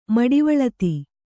♪ maḍivaḷati